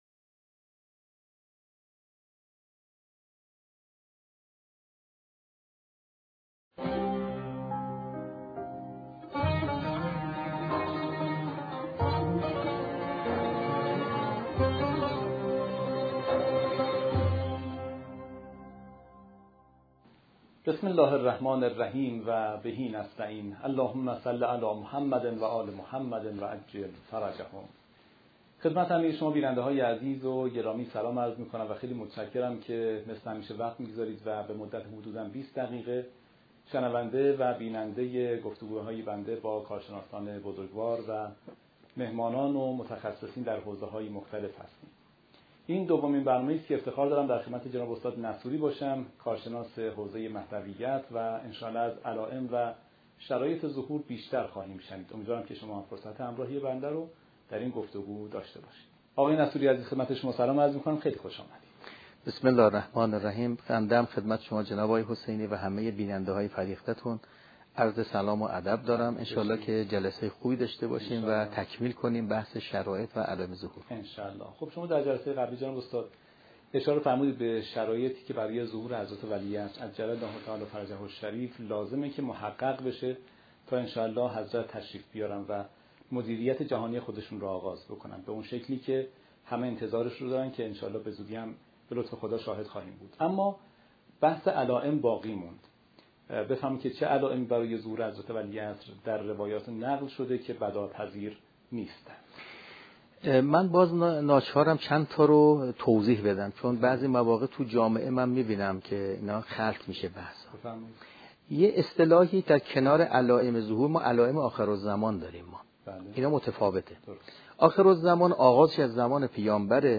برنامه حاضر دومین جلسه گفتگو با کارشناس مهدویت است که به بررسی علائم و شرایط ظهور حضرت ولیعصر(عج)می‌پردازد.